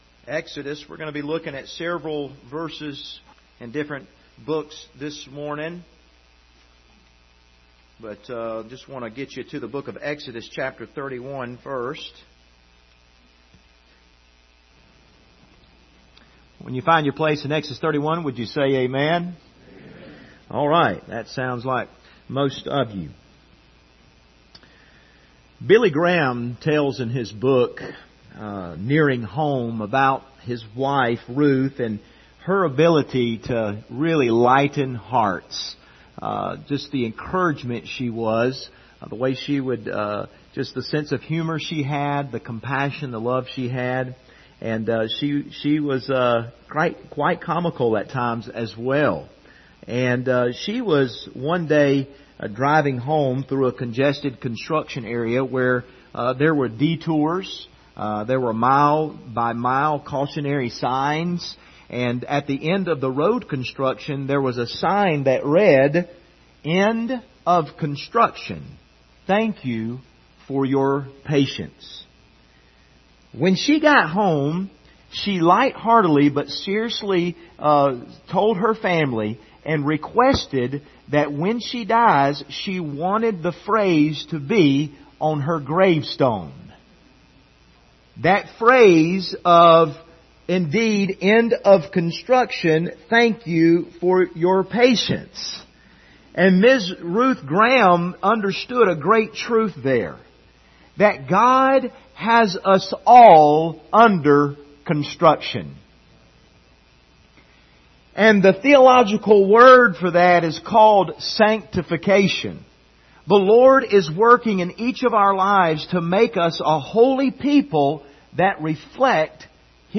Service Type: Sunday Morning Topics: holiness , sanctification